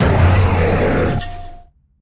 Cri de Rugit-Lune dans Pokémon HOME.